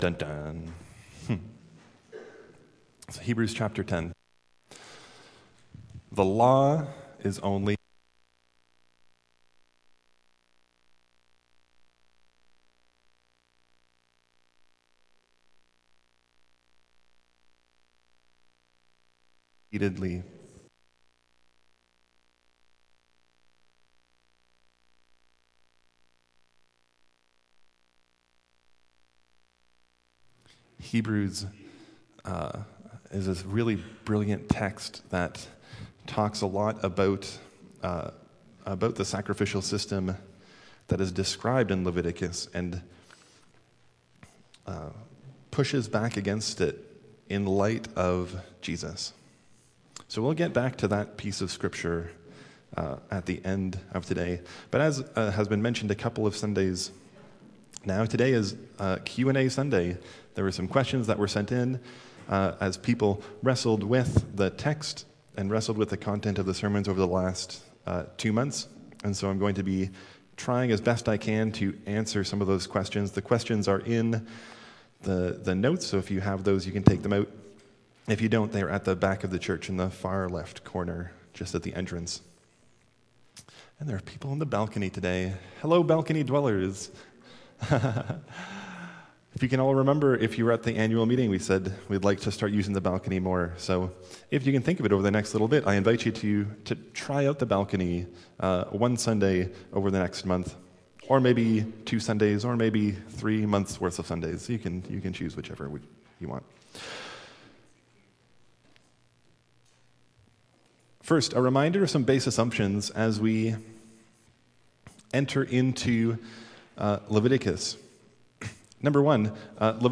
Download Download Reference Leviticus 1–6 From this series Current Sermon Leviticus #8